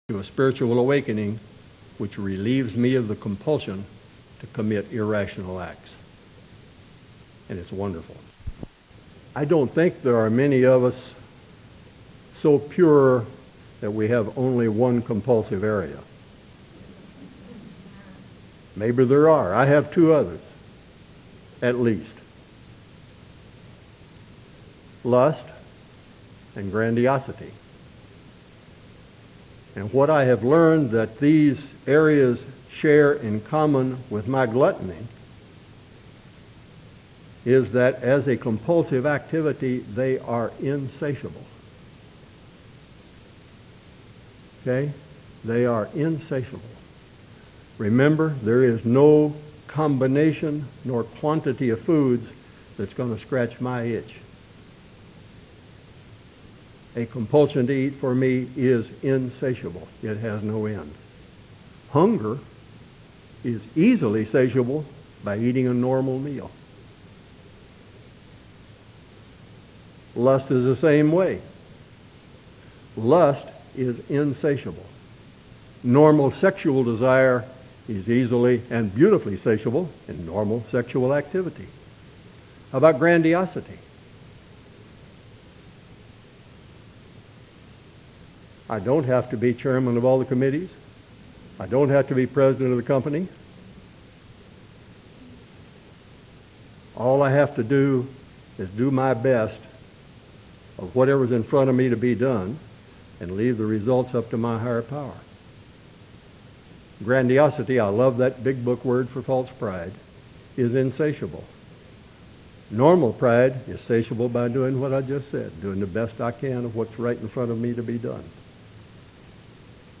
Speaker Podcasts & Audio Files
Region 1 Convention, Seattle 2001